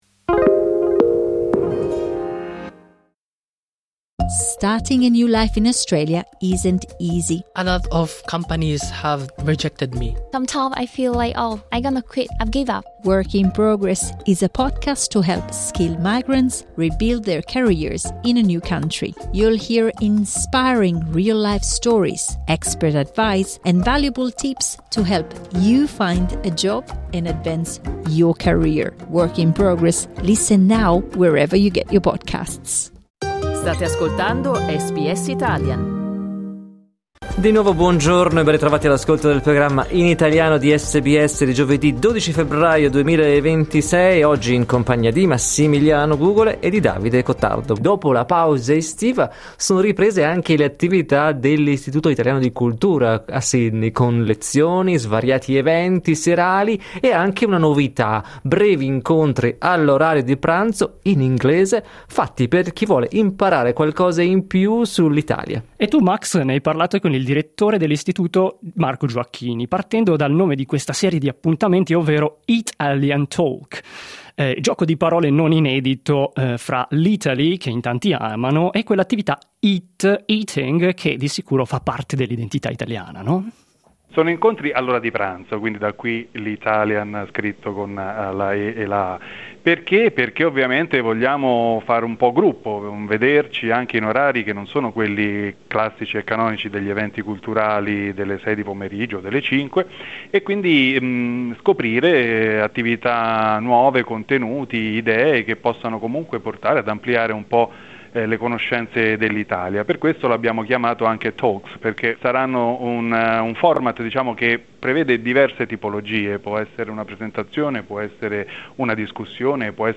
ha spiegato di cosa si tratta ai microfoni di SBS Italian, partendo dal nome dell'evento.